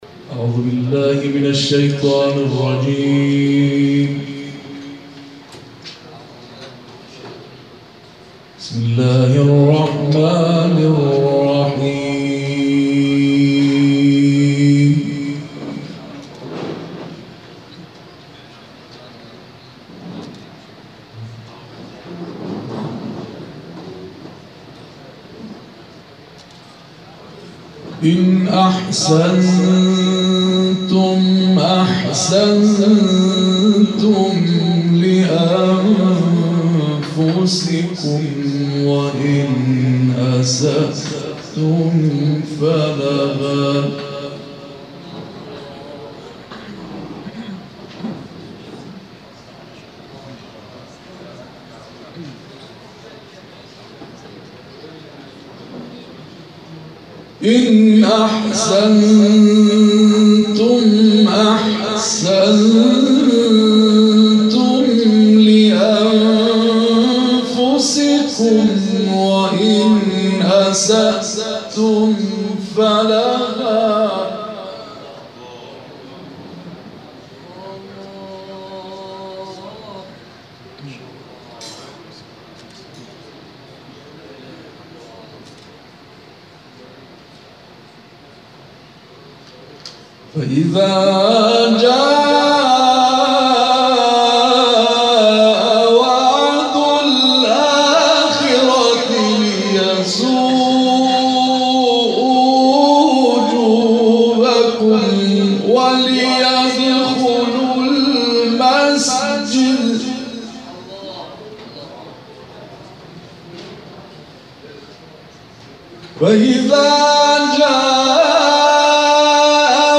گروه فعالیت‌های قرآنی: محفل انس باقرآن کریم روز گذشته، نهم تیرماه در تالار پردیس ابن‌بابویه برگزار شد.